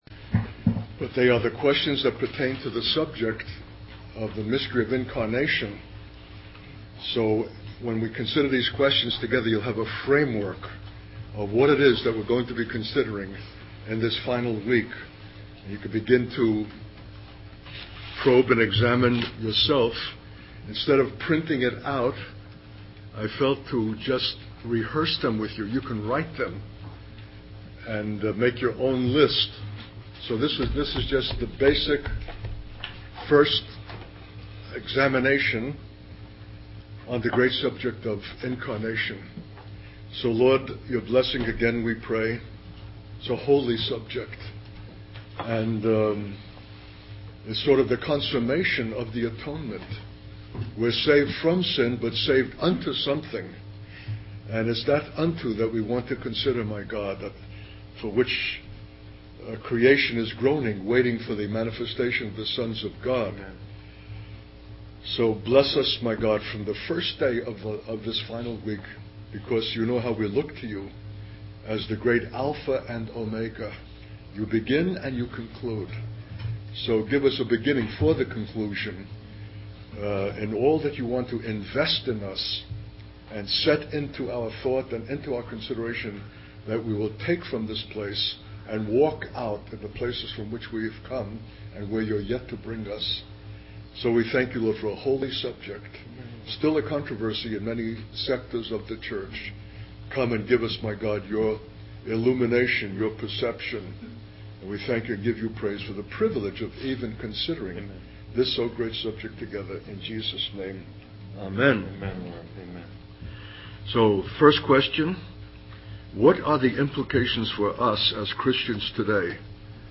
In this sermon, the preacher emphasizes the significance of the incarnation of Jesus Christ as the fulfillment of God's cosmic design. He highlights that the proclamation of the gospel of the kingdom to all nations and the inclusion of the Gentiles are essential for the coming of the deliverer. The preacher also discusses the unity and uniqueness of believers in the church, emphasizing that God invests himself in the individuality of each person.